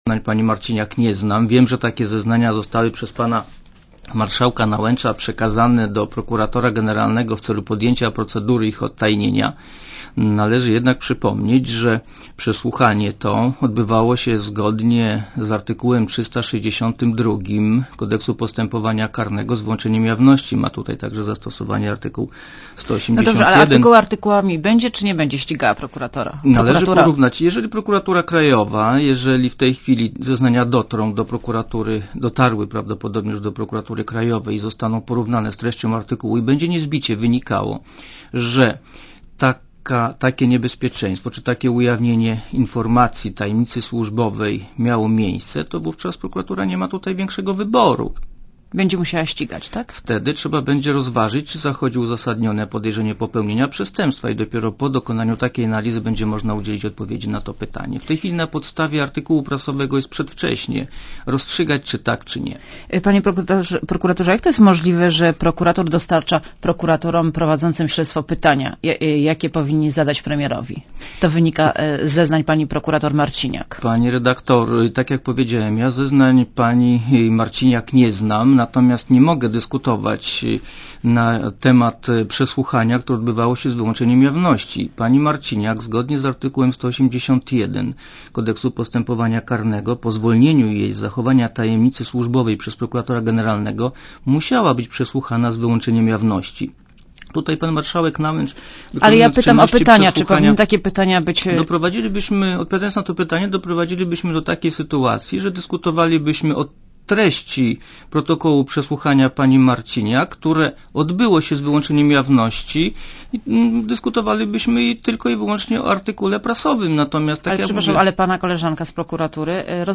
wywiad_8wrzesnia.mp3